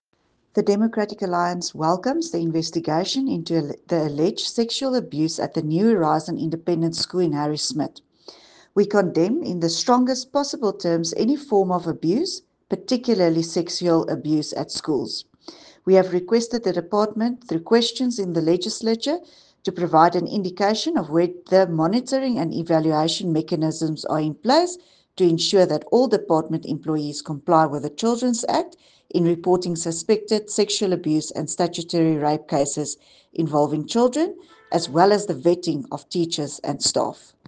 Afrikaans soundbites by Dulandi Leech MPL and